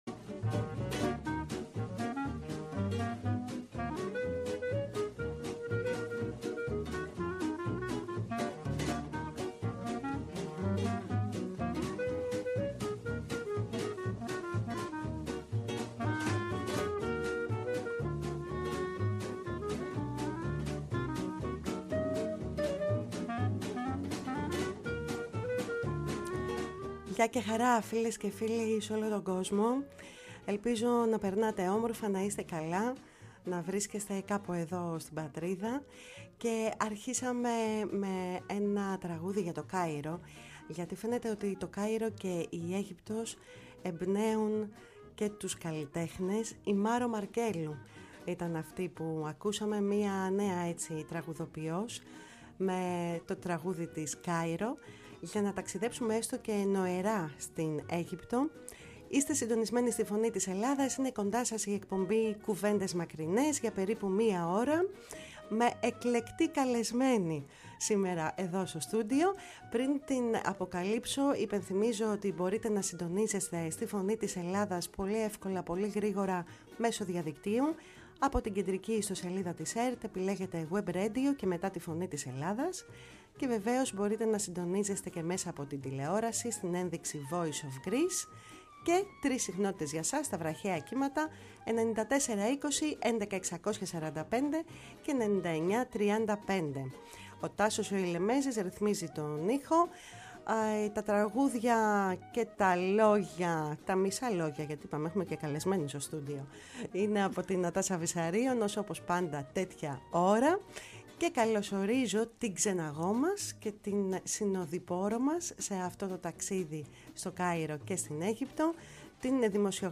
Αφιερωματική εκπομπή στην ελληνική παροικία Καΐρου και στην Ελληνική Κοινότητα φιλοξένησε η Ελληνική Ραδιοφωνία την Τετάρτη 31 Ιουλίου.